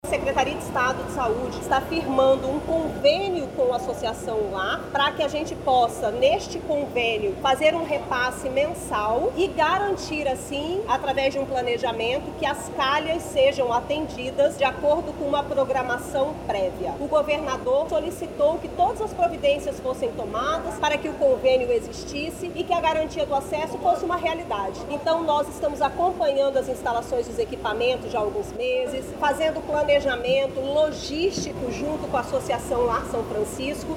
A secretária estadual de Saúde, Nayara Maksoud, ressalta que o convênio entre a Associação Franciscana e o Estado, prevê repasses de verbas por parte do Governo para a manutenção do programa.